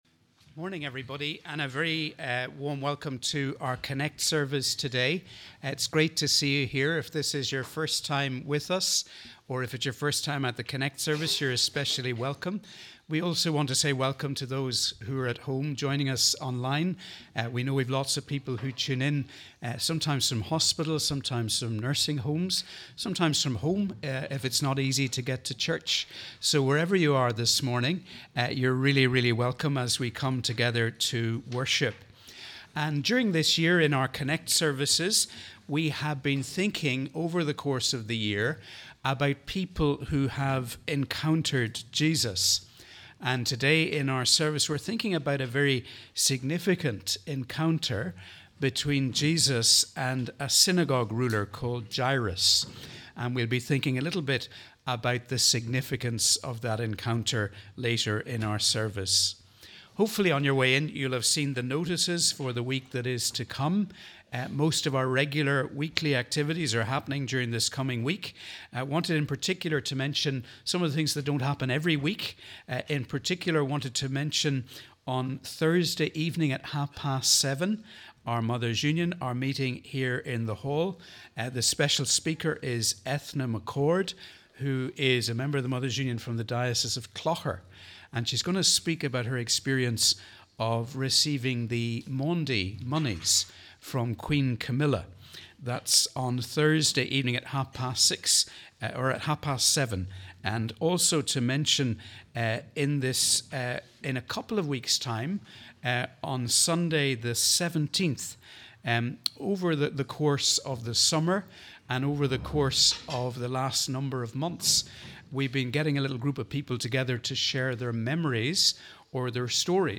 Wherever you are, we welcome you to our Connect service on the 4th Sunday before Advent.